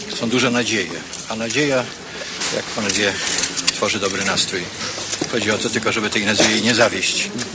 Mówił generał Wojciech Jaruzelski zaraz po oddaniu swojego głosu.